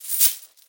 Category 🎮 Gaming
accomplished alert amazing awesome bells bonus coin collect sound effect free sound royalty free Gaming